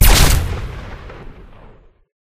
Wpn_rifle_laser_fire_3d.ogg